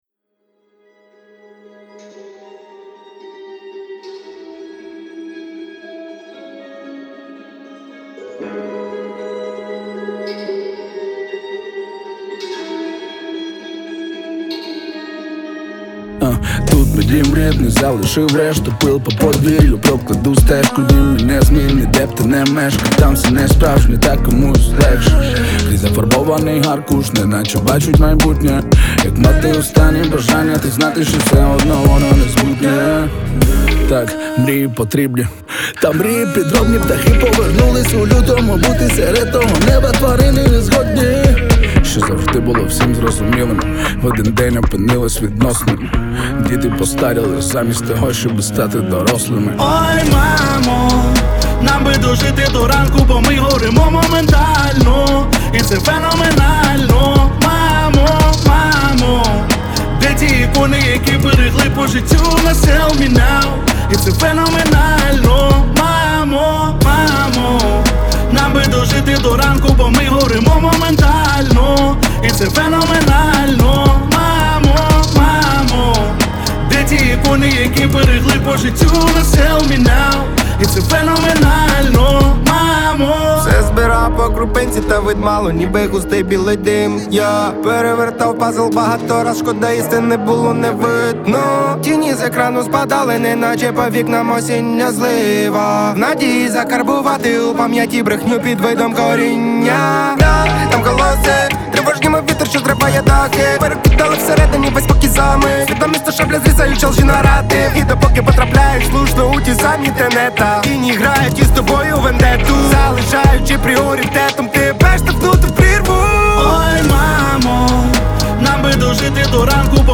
• Жанр: Hip-Hop